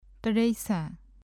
1. 歯茎弾き音 alveolar tap の例
တိရစ္ဆာန် [tăreiʔsʰàɴ~tăleiʔsʰàɴ ]動物 ＜ Pali. tiracchāna